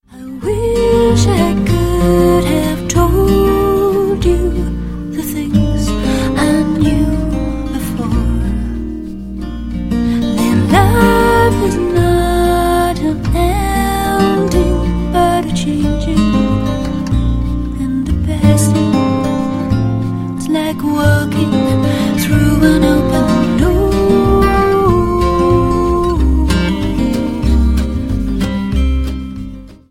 • Sachgebiet: Celtic